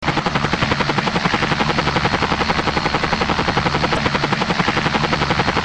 Helicopter mp3 sound ringtone free download
Sound Effects